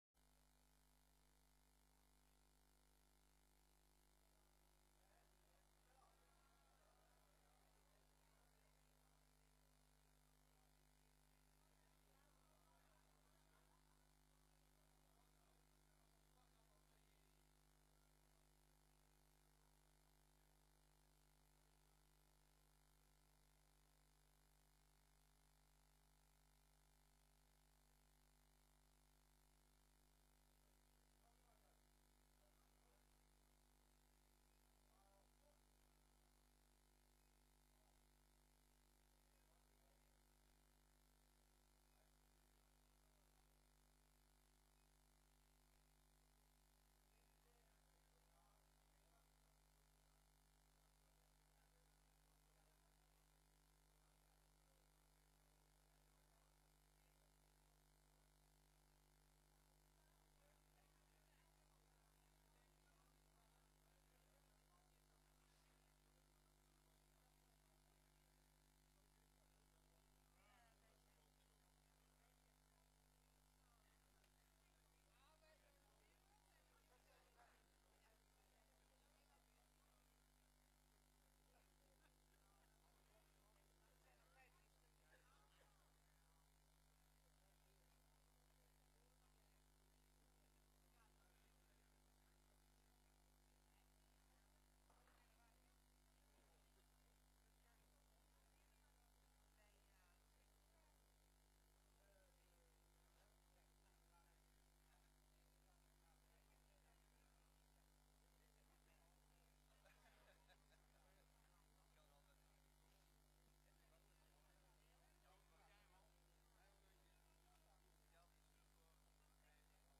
Vergadering : Gemeenteraad
Locatie: Raadzaal